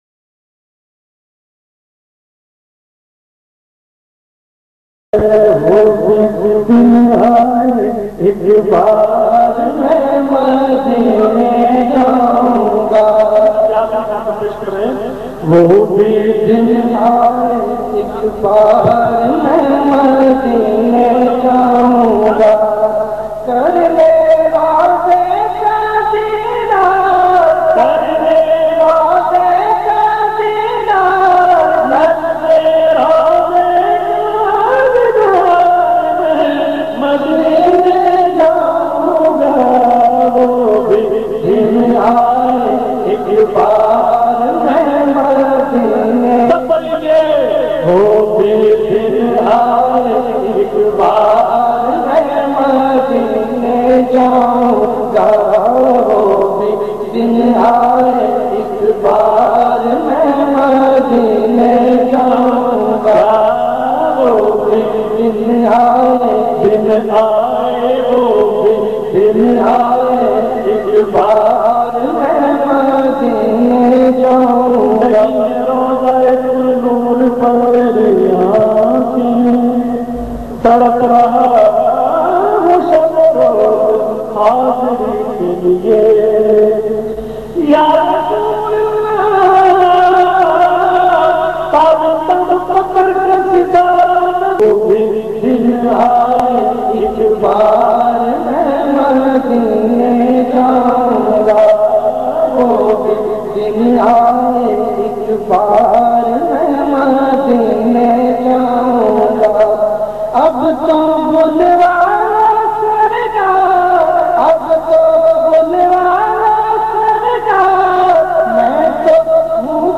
naat sharif
in best audio quality